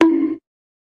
bonk.mp3